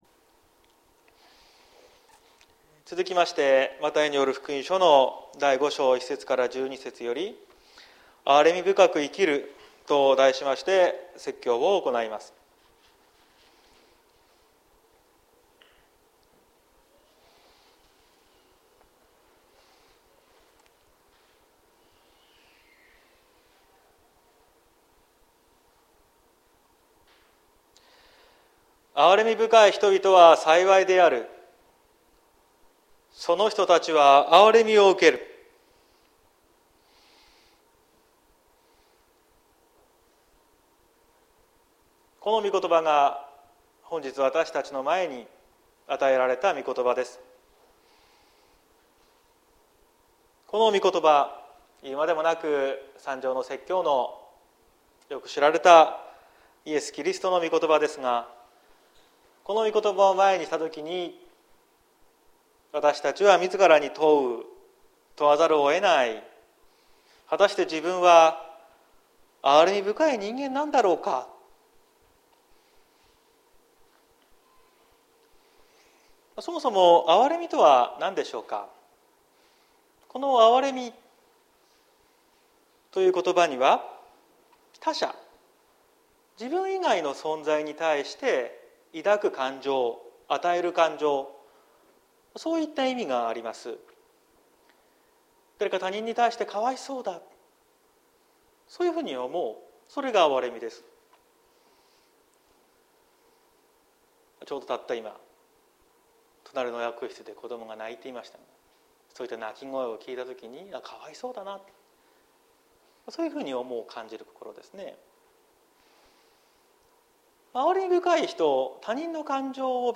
2022年07月31日朝の礼拝「憐れみ深く生きる」綱島教会
綱島教会。説教アーカイブ。